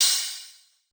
Crashes & Cymbals
pbs - zay crash [ OpHat ].wav